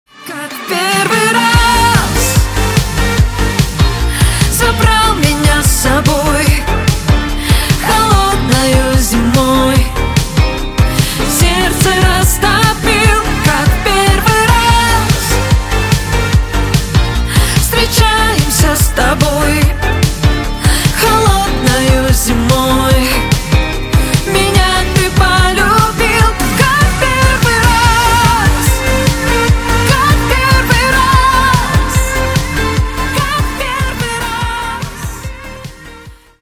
Поп Рингтоны
Скачать припев песни